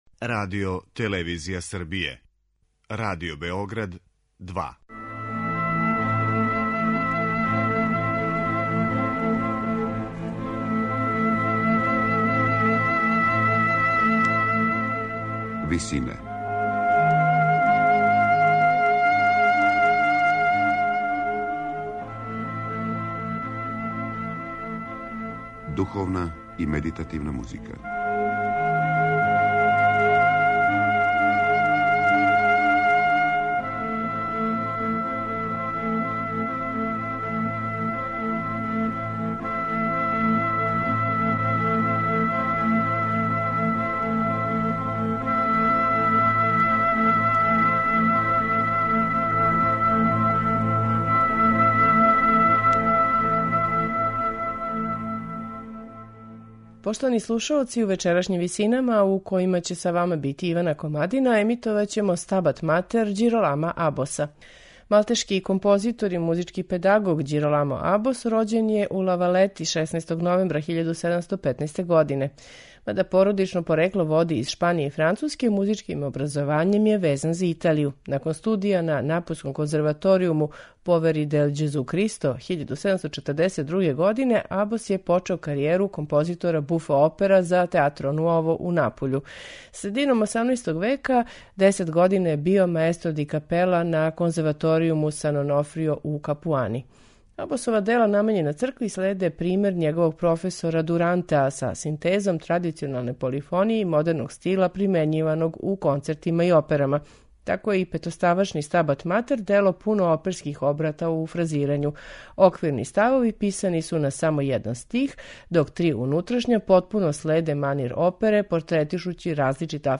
Тако је и Абосов "Стабат матер" дело пуно оперских обрaта у фразирању, што се посебно види у три унутрашња става у којима се различита афективна стања портретишу кроз смењивања сола, дуета и трија, на начин који срећемо у оперским финалима тог доба.
сопрани
алт и ансамбл Страдивариа